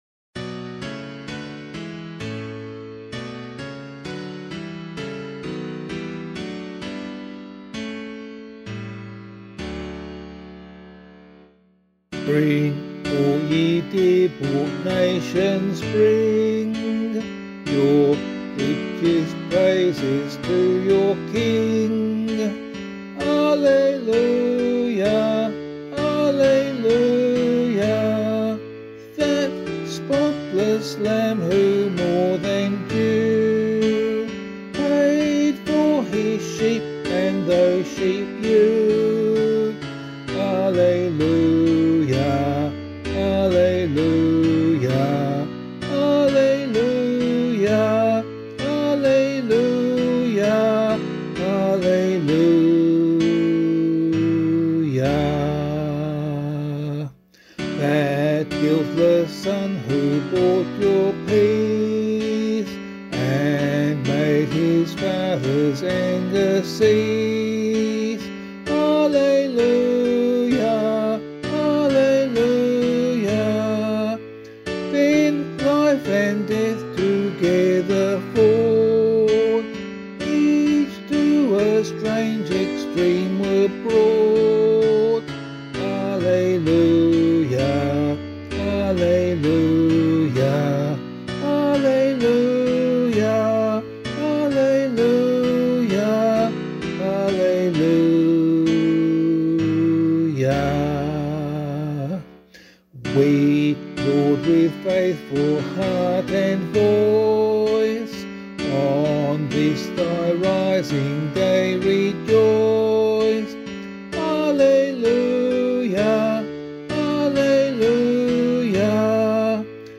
vocal
Bring All Ye Dear-Bought Nations Bring [Blount - LASST UNS ERFREUEN] - vocal [DO74].mp3